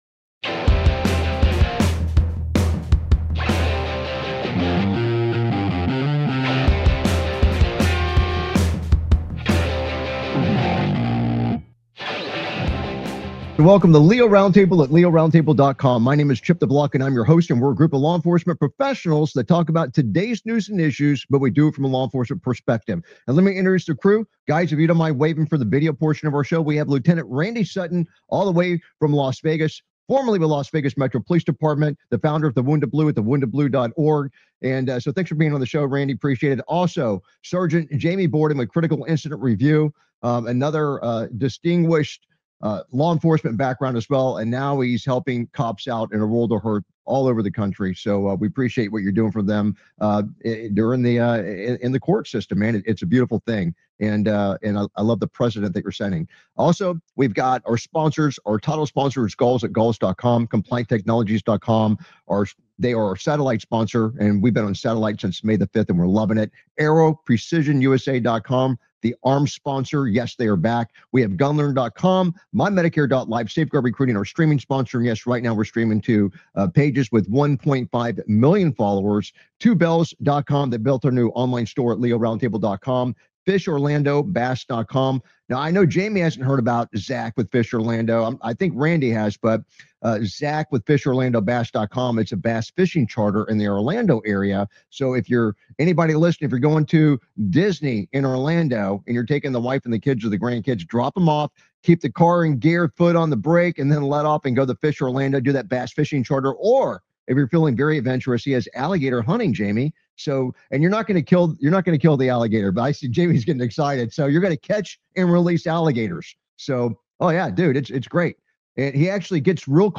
Talk Show Episode, Audio Podcast, LEO Round Table and S10E182, Repeat Offender Who Murdered Woman Isn’t Getting Off Easy Thanks To DOJ on , show guests , about Repeat offender who murdered woman isn’t getting off easy thanks to DOJ,Trump Administration gets big wins in court,'COPS' teams up with the Wounded Blue for big announcement,Officers involved in the fatal shooting of armed suspect justified,Woman armed with large knife shot by cops during attempted arrest, categorized as Entertainment,Military,News,Politics & Government,National,World,Society and Culture,Technology,Theory & Conspiracy